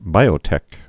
(bīō-tĕk)